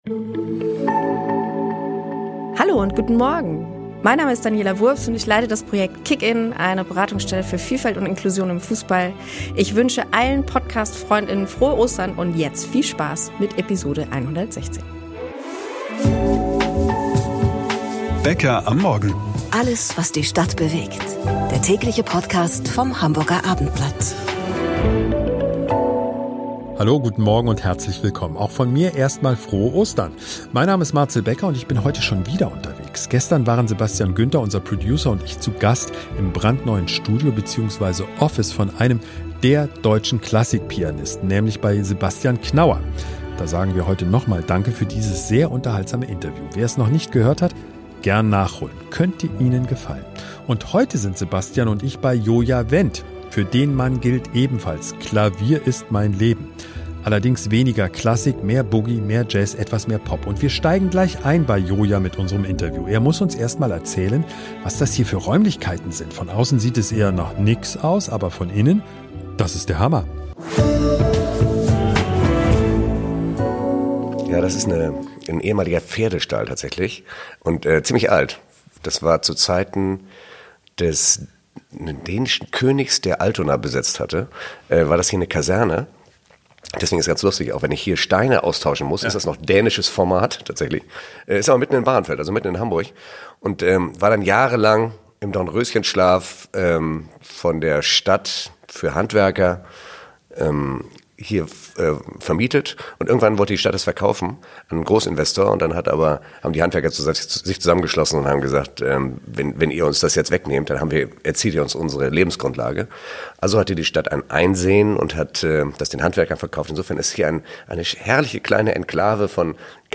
- Starpianist Joja Wend bei uns im MINI-Konzert - Joe Cocker und Joja Wendt in der gleichen Kneipe - und dann?? - Jojas dunkelste Stunde: Schwerer Unfall, zertrümmerte Hand